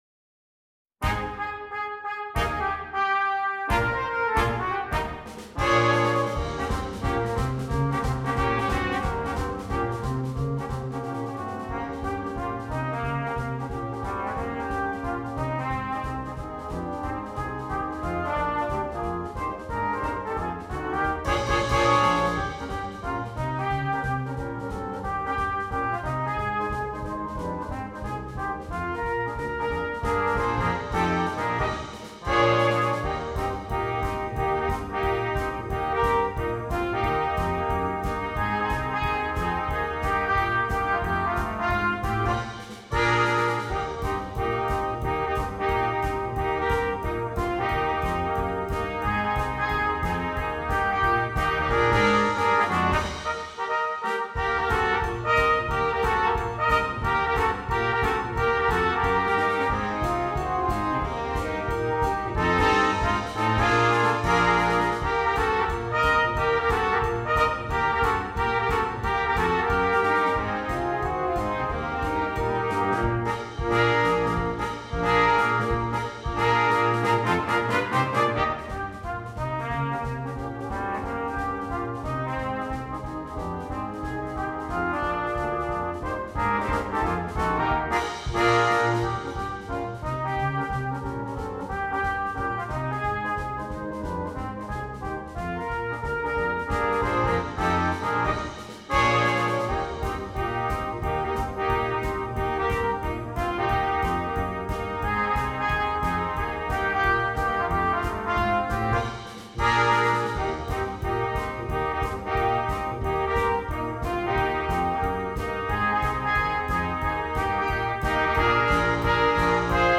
Brass
Brass Quintet (optional Drum Set)